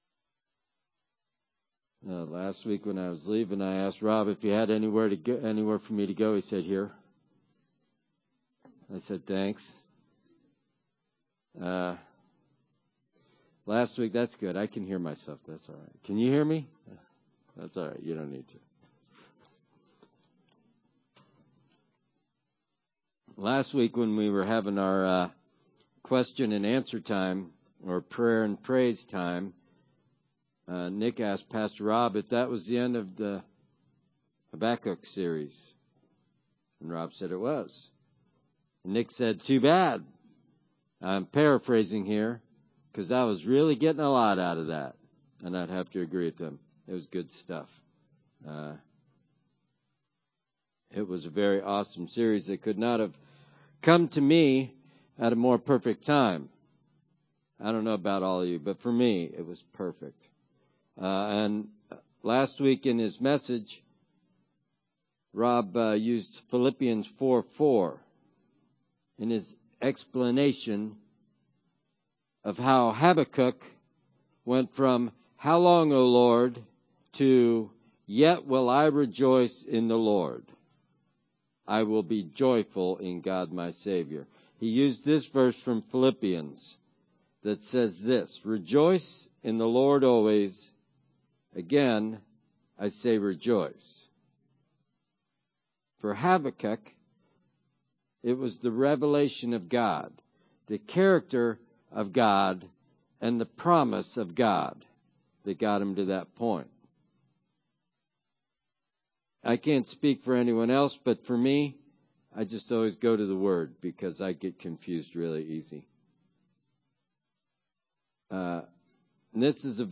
Passage: Philippians 4:4-9 Service Type: Sunday Service Bible Text